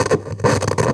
pgs/Assets/Audio/Electricity_Hums/radio_tv_electronic_static_02.wav at master
radio_tv_electronic_static_02.wav